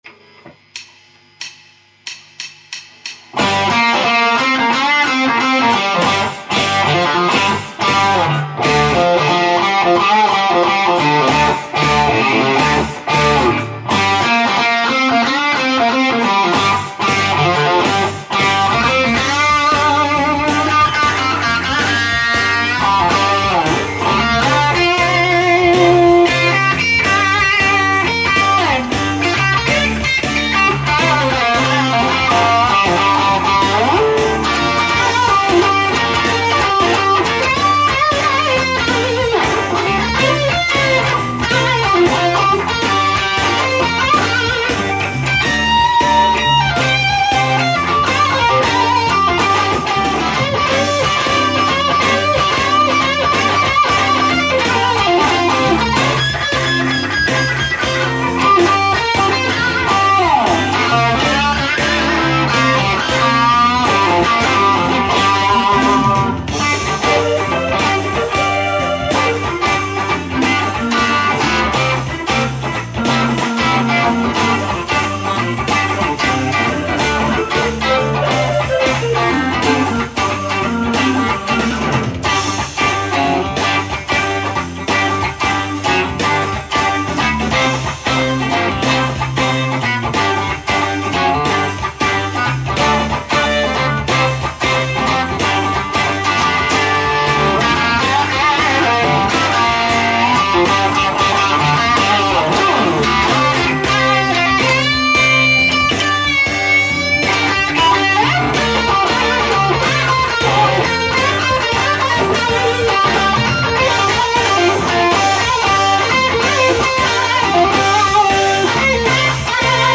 Abwarten und Tee trinken Hab mal was angehängt, solch "Zerre" nutz ich. Hab hier das Vox Cooltron Duel Overdrive und den Pickupbooster eingeschaltet.